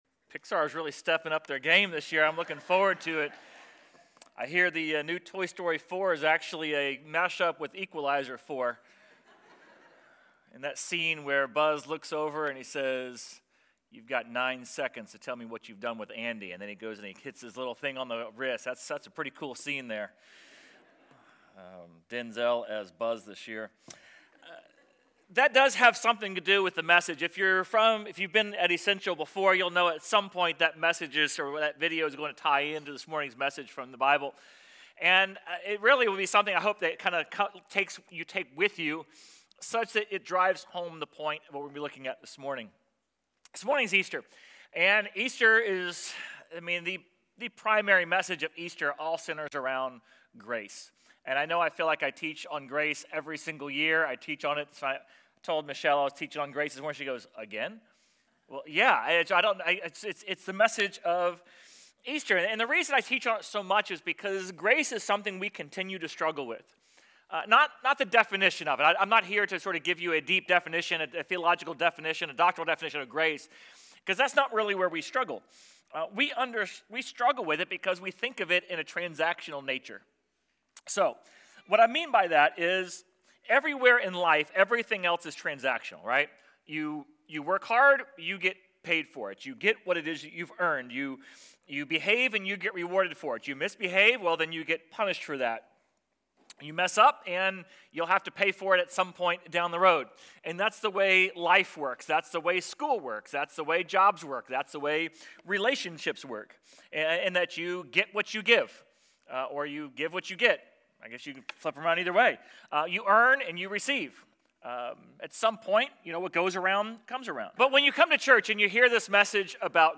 Sermon_4.5.26.mp3